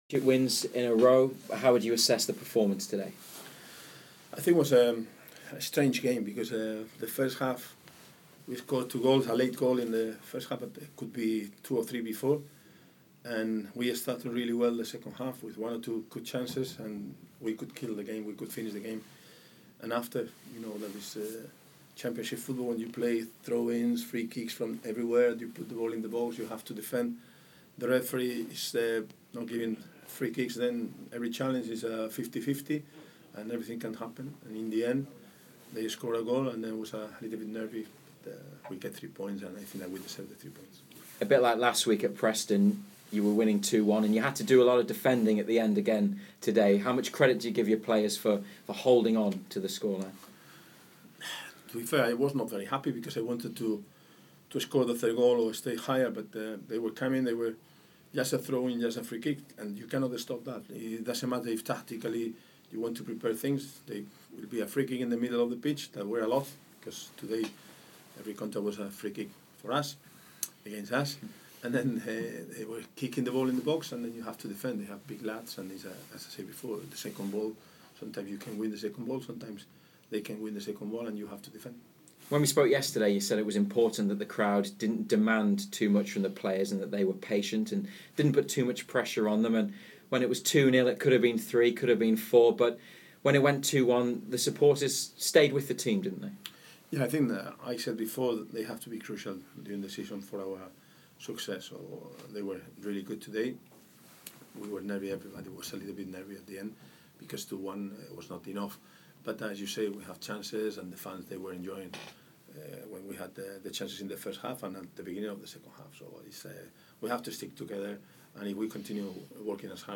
Rafa Benítez spoke to BBC Newcastle after the Magpies beat Cardiff City to make it eight straight wins in all competitions.